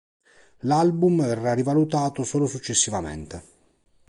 suc‧ces‧si‧va‧mén‧te
/sut.t͡ʃes.si.vaˈmen.te/